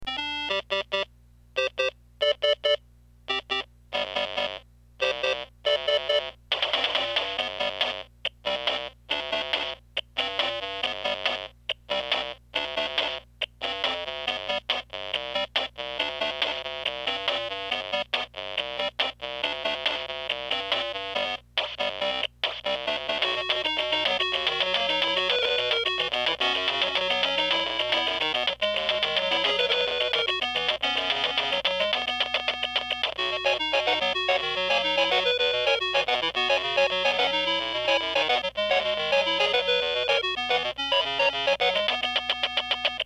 All Atari Lynx II's used for these speaker tests, had there volume control wheel turned to Maximum sound volume out and the recording microphone was placed the same distance from each Lynx II built in speaker.  The Atari Game cartridge used to run the sound tests is the Lynx California Games start up screen and then the second California 4 game selection screen.
Used Lynx II w/Best upgrade speaker installed.MP3
Upgraded Lynx II speaker.mp3